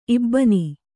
♪ ibbani